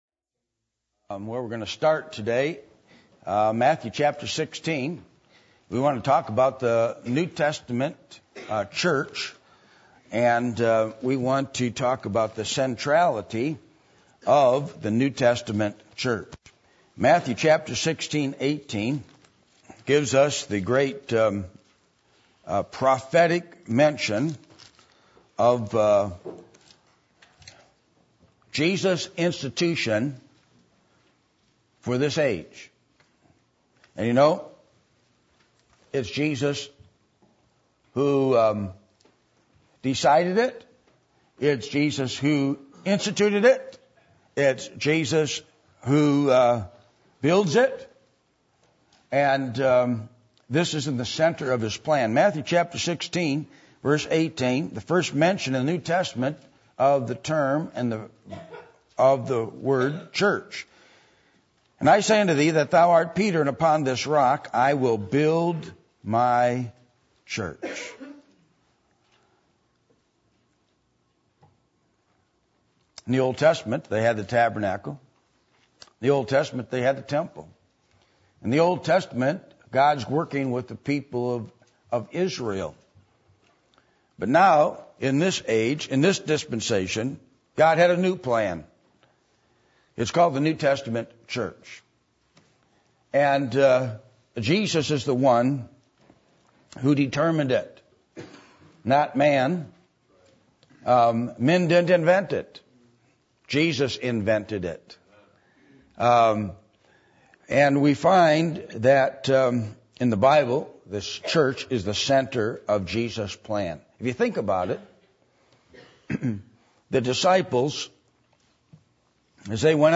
Passage: Matthew 16:1-20 Service Type: Sunday Morning %todo_render% « Why We Should Read Our Bible Every Day Can God Bless America?